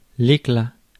Ääntäminen
UK : IPA : /ˈslɪv.əː/ US : IPA : /ˈslɪ.vɚ/